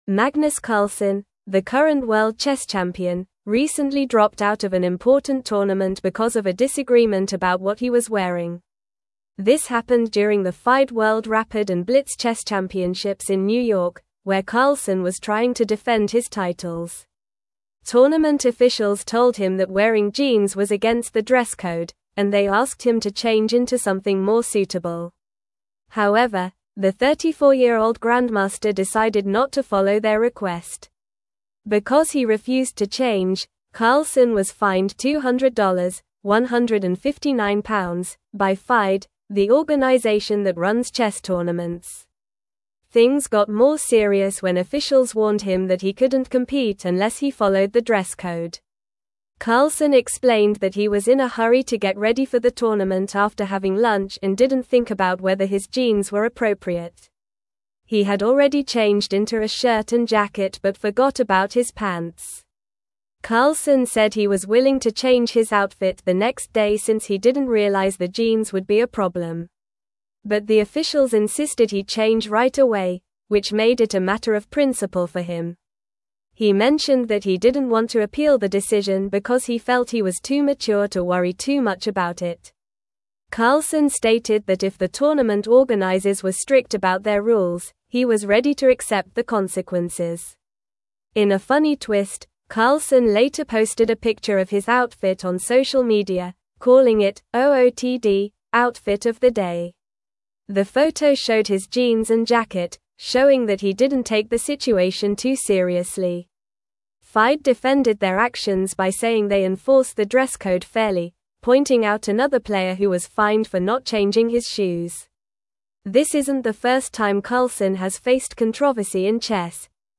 Normal
English-Newsroom-Upper-Intermediate-NORMAL-Reading-Carlsen-Withdraws-from-Tournament-Over-Dress-Code-Dispute.mp3